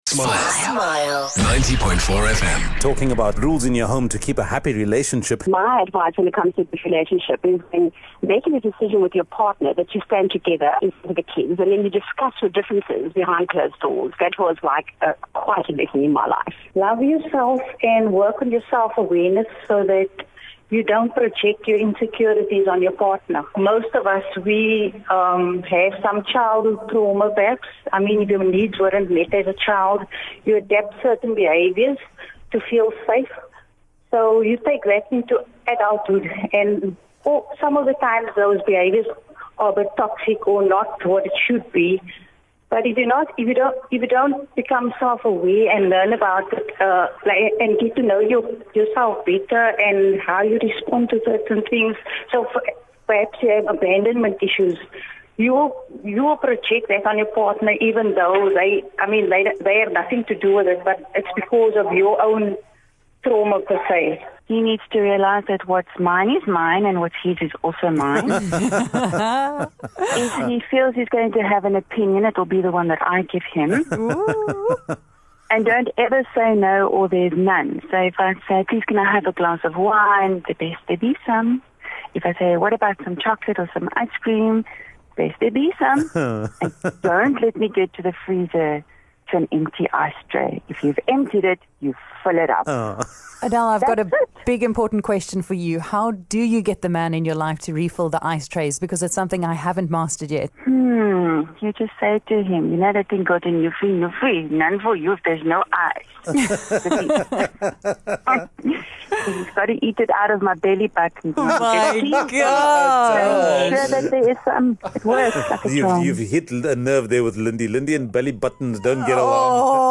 Smile Breakfast asked listeners to call in and tell us if they had to implement any rules to keep the peace at home.